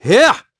Siegfried-Vox_Attack2.wav